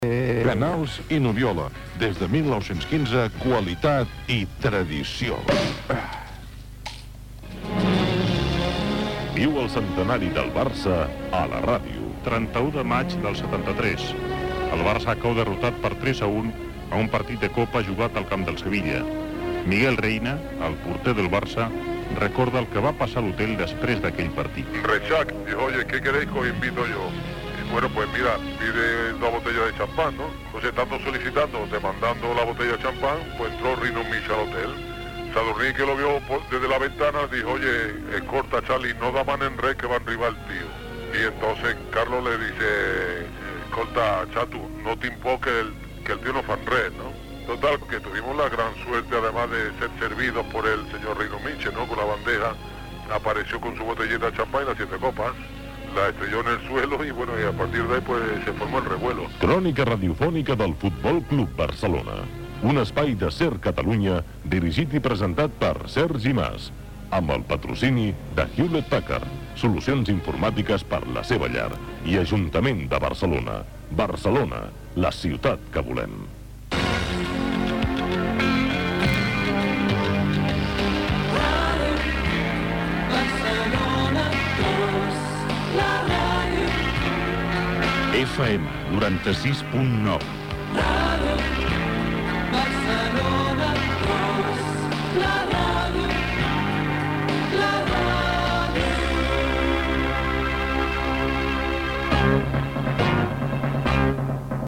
Publicitat, promoció del Centenari del FC Barcelona i indicatiu.
FM